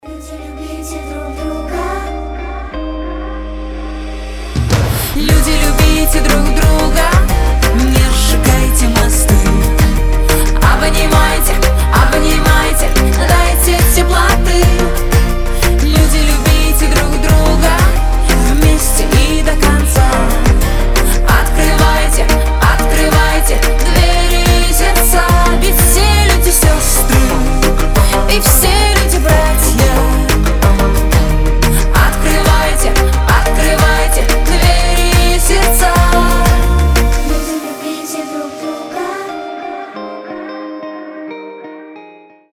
• Качество: 320, Stereo
поп
позитивные
женский вокал
добрые
детский голос